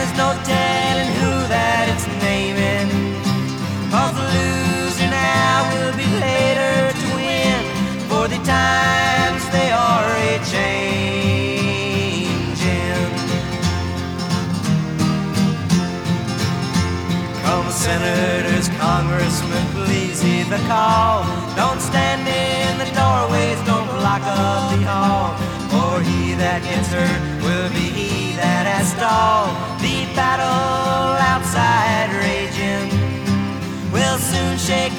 Жанр: Поп музыка / Рок / Фолк